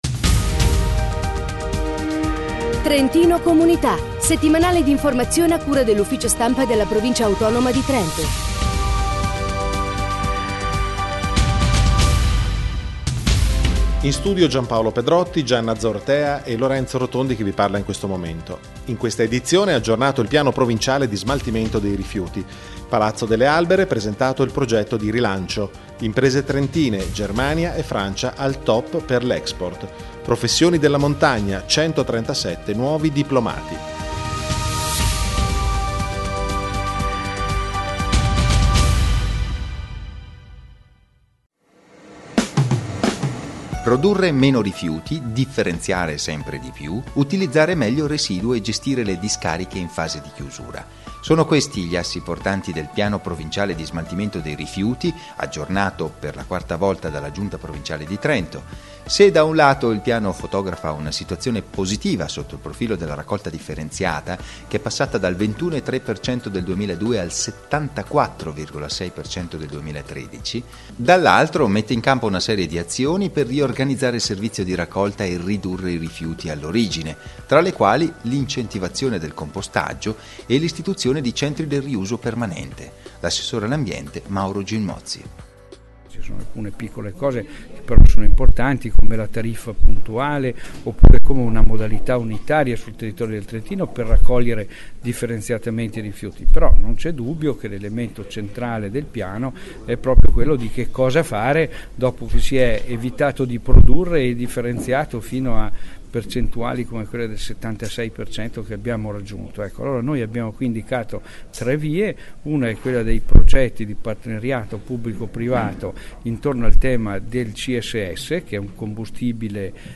Notiziario Ambiente Cultura Economia, imprese e attività produttive